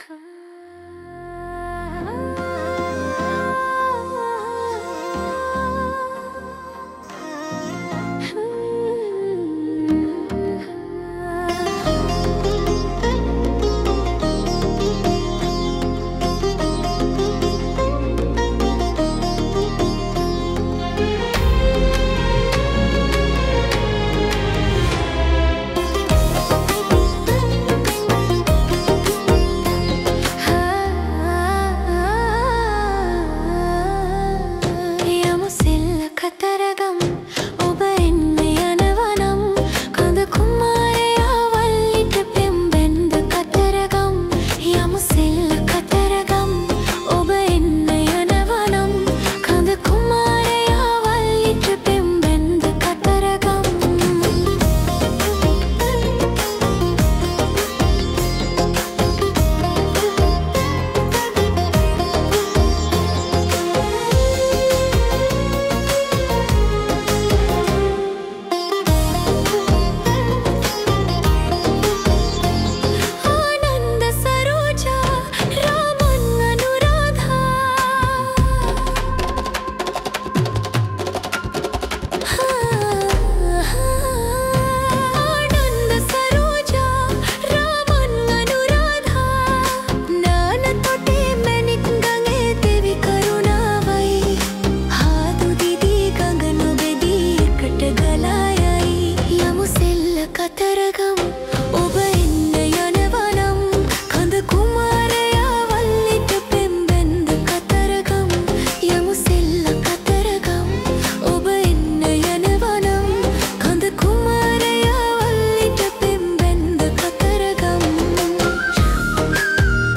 singer-songwriter and talented artist
Afro-pop